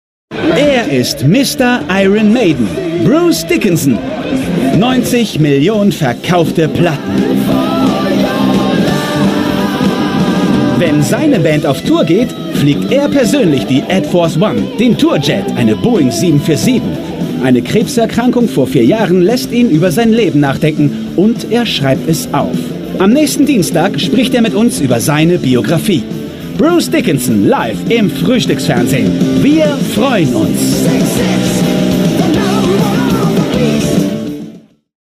Jung - dynamisch - voll - warm - variable
Sprechprobe: Sonstiges (Muttersprache):
dynamic - full - warm